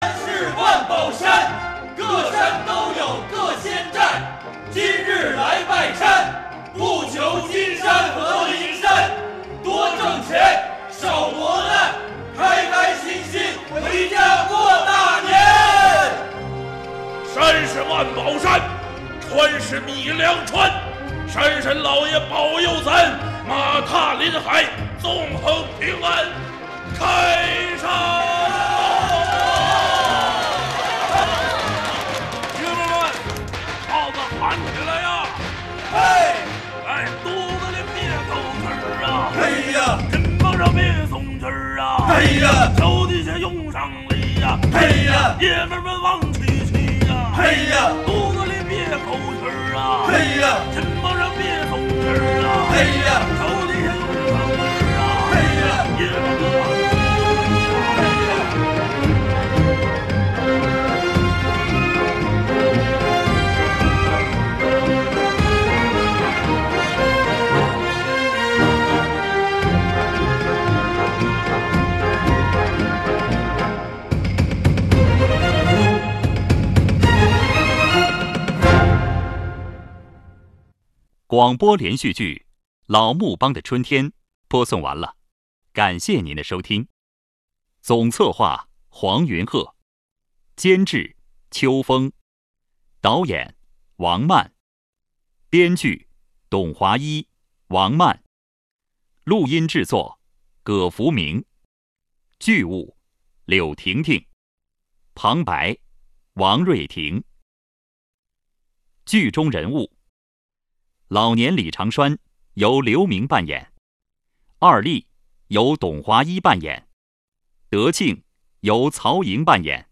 • 广播类型：微广播剧